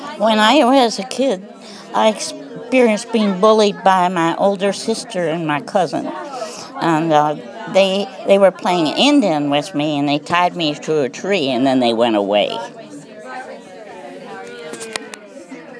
These stories were recorded at the opening of the Yellow Springs Arts Council’s show “Art for Social Change” in response to sharing our progress at Mills Lawn with Project Peace.